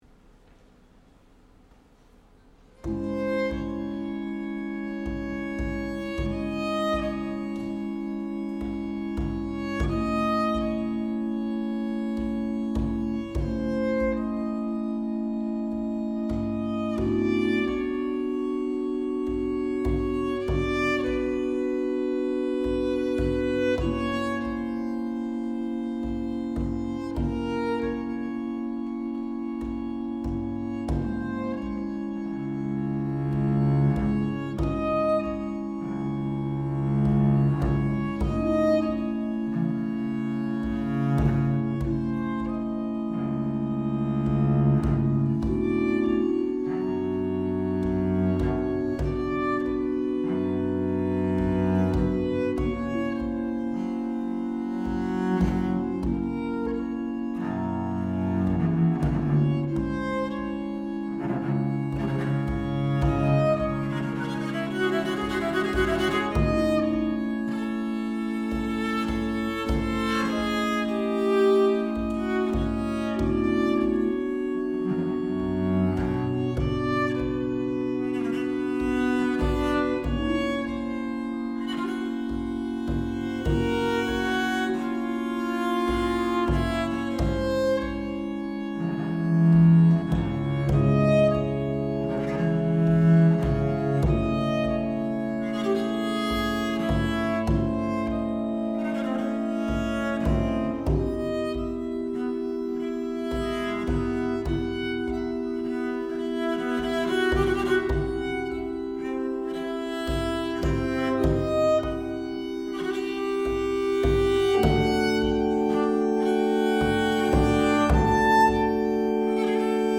bass viol